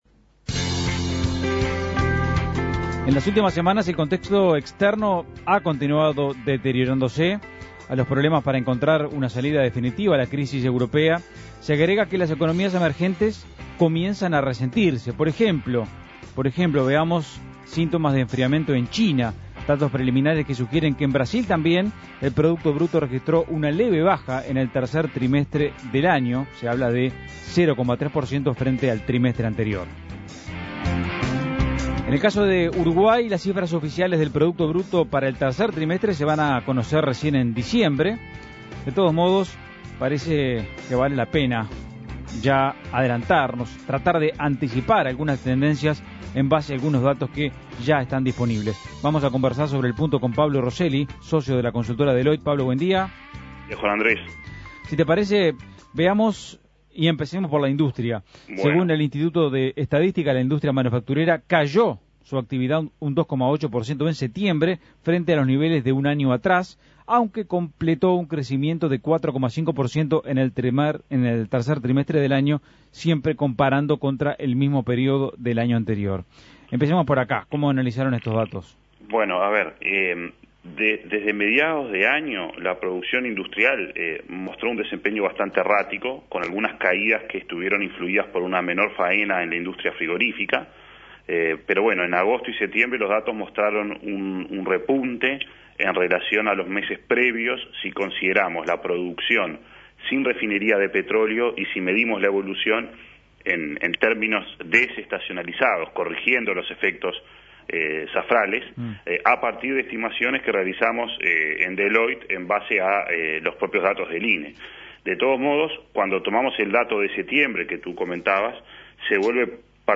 Análisis Económico La actividad económica mostraría un nuevo incremento en el tercer trimestre de 2011.